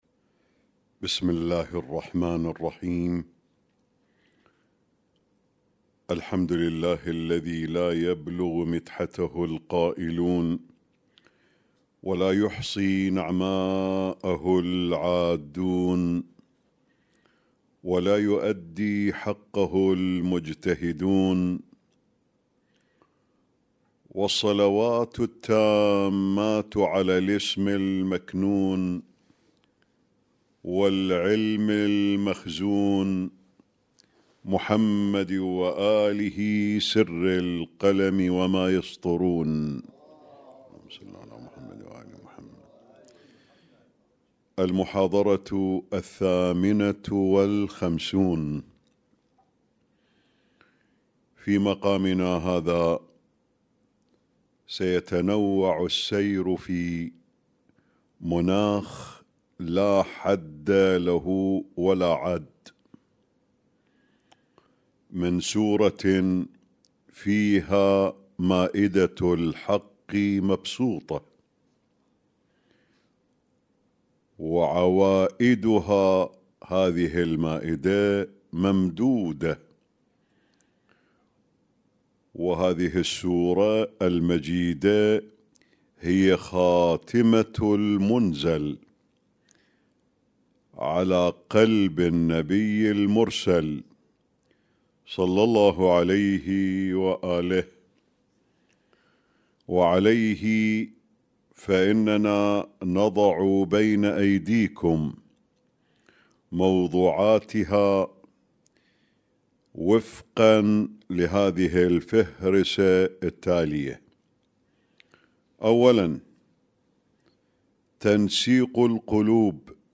اسم التصنيف: المـكتبة الصــوتيه >> الدروس الصوتية >> الرؤية المعرفية الهادفة